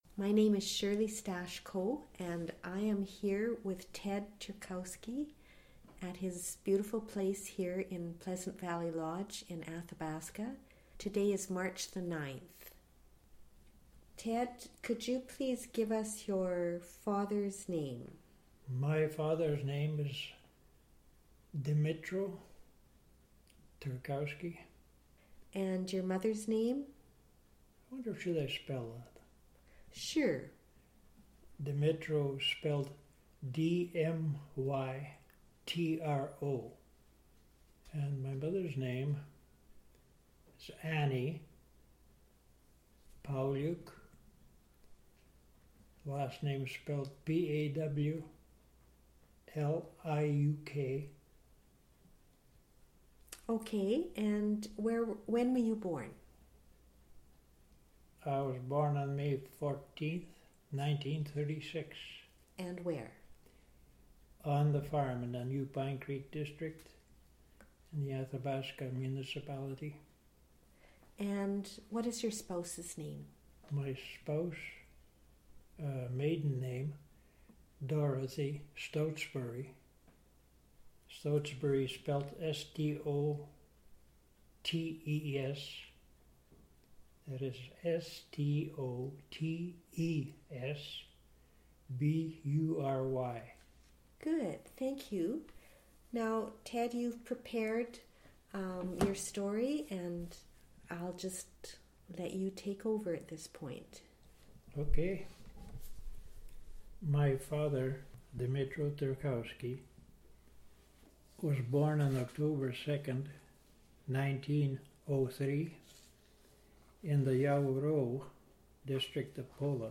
Audio interview,